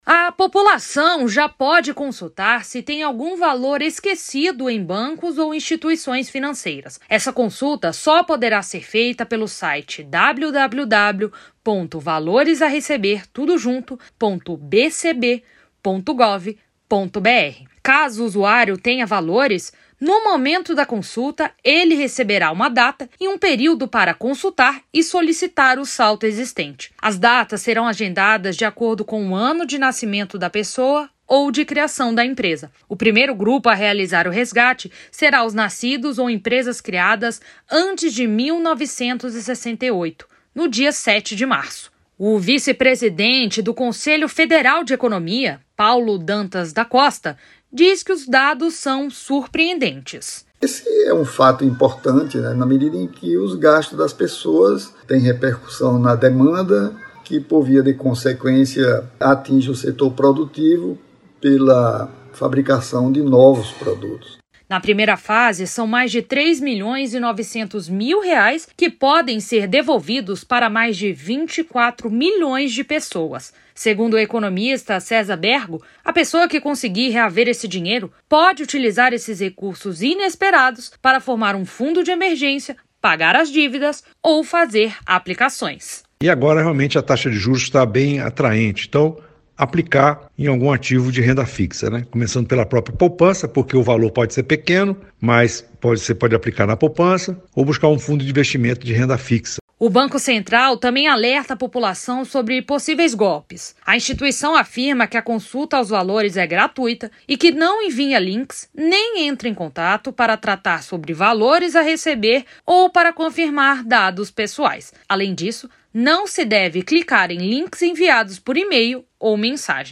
MATÉRIA EM ÁUDIO